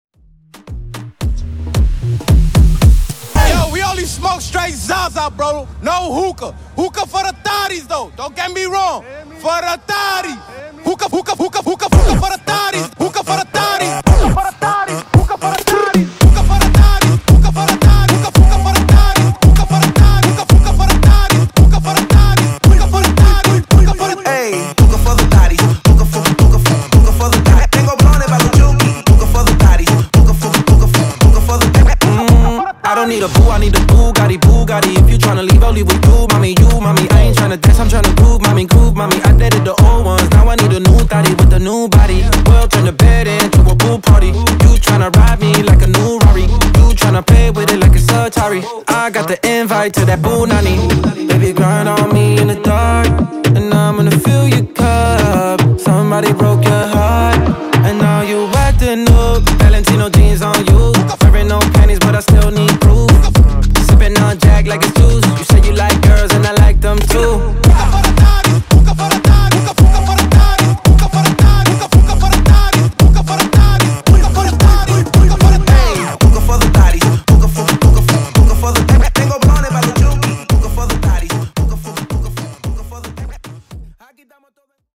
Genre: RE-DRUM
Dirty BPM: 116 Time